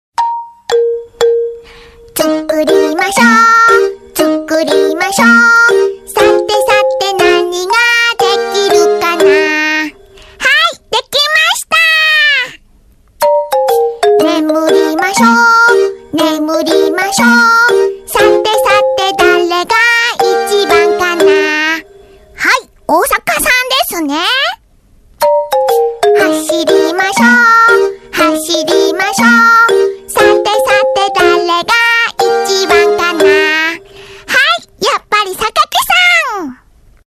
背景音乐